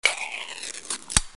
Lata de bebida carbonatada que se abre
El ruido de gas que sale de la lata de bebida cuando se abre.